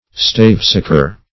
Search Result for " stavesacre" : The Collaborative International Dictionary of English v.0.48: Stavesacre \Staves"a`cre\ (st[=a]vz"[=a]`k[~e]r), n. [Corrupted from NL. staphis agria, Gr. stafi`s dried grape + 'a`grios wild.]
stavesacre.mp3